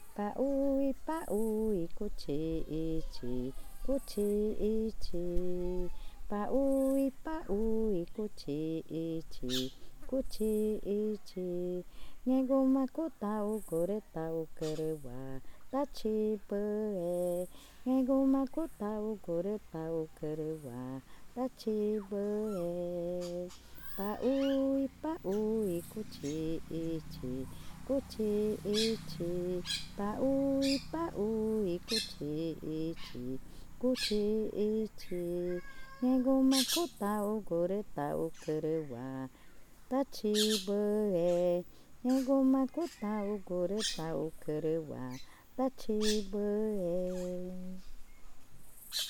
Canción infantil sobre la fariña